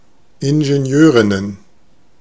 Ääntäminen
Ääntäminen Tuntematon aksentti: IPA: [ɪnʒenˈjøːʀɪnən] Haettu sana löytyi näillä lähdekielillä: saksa Käännöksiä ei löytynyt valitulle kohdekielelle. Ingenieurinnen on sanan Ingenieurin monikko.